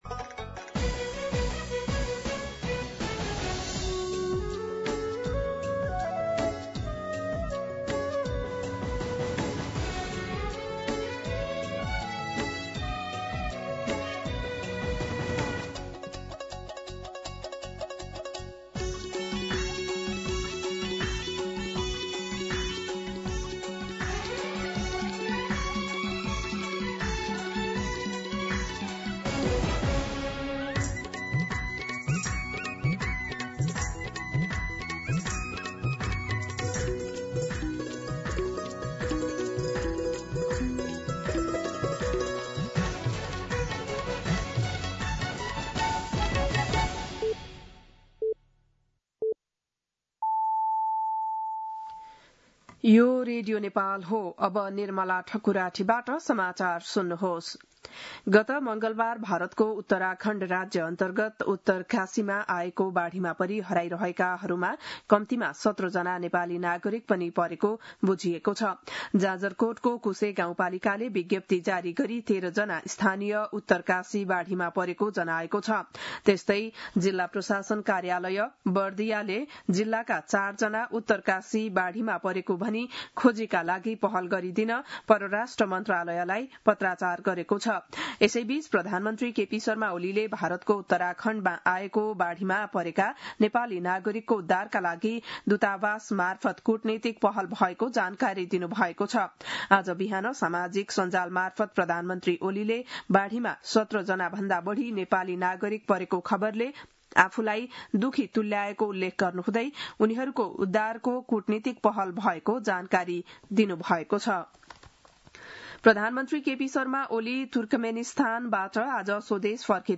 बिहान ११ बजेको नेपाली समाचार : २३ साउन , २०८२
11-am-Nepali-News-.mp3